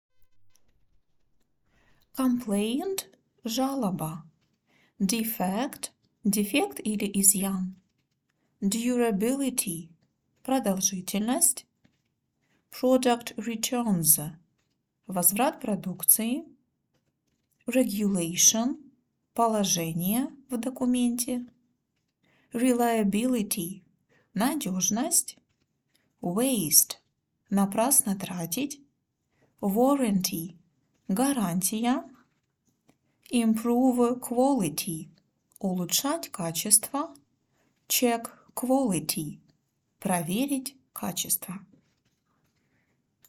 • прослушивайте озвученные английские фразы ежедневно, повторяйте за диктором сначала английский вариант, а затем и русский перевод;